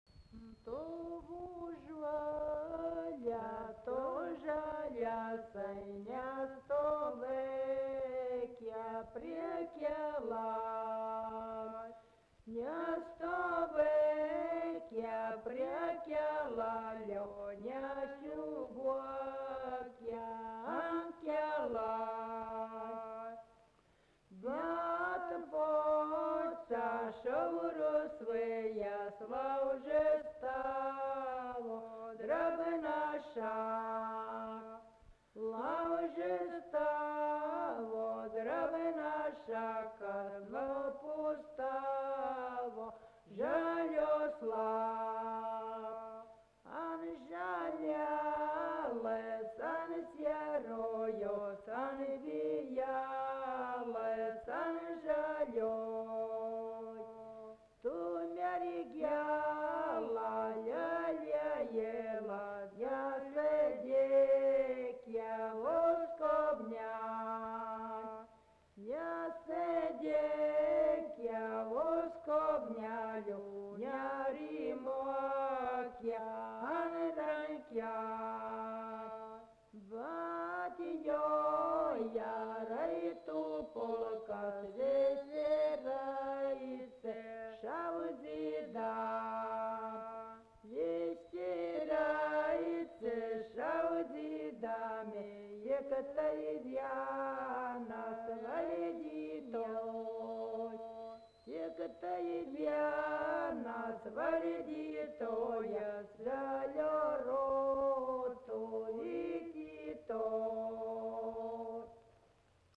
Subject daina
Erdvinė aprėptis Liškiava
Atlikimo pubūdis vokalinis